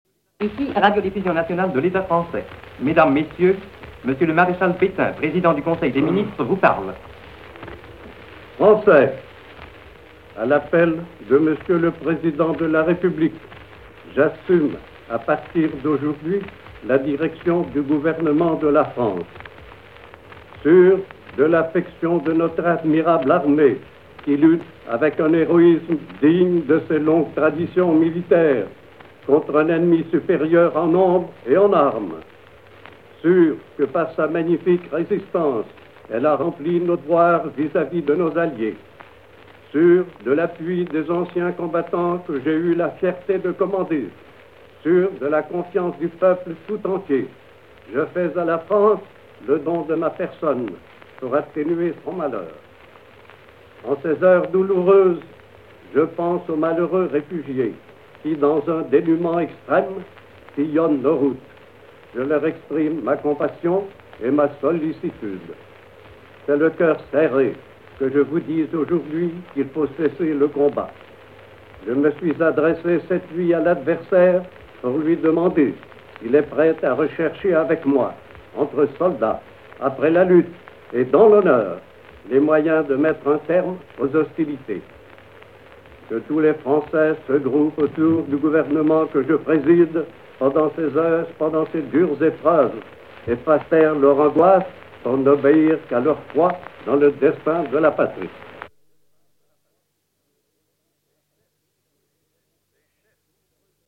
12 h 30 : allocution du maréchal Pétain, président du Conseil ; il est diffusé par toutes les radios.
17 juin 1940. — Allocution du maréchal Pétain
L’enregistrement de l’allocution